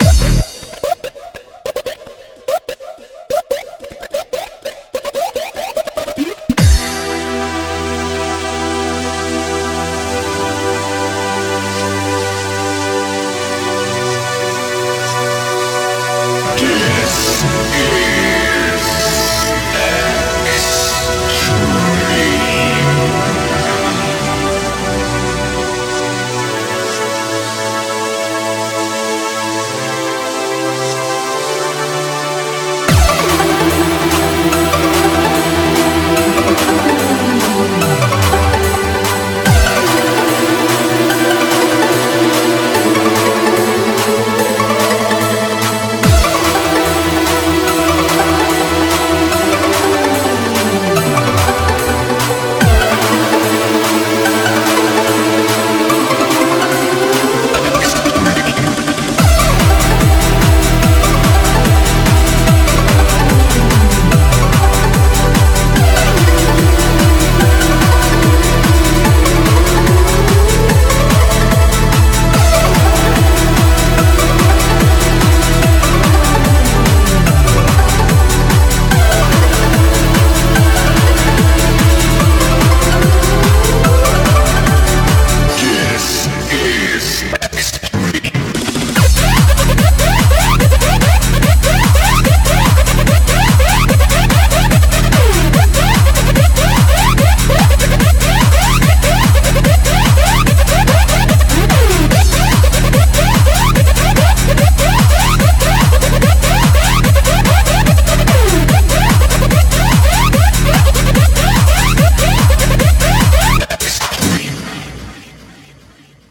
BPM73-292
Audio QualityMusic Cut